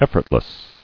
[ef·fort·less]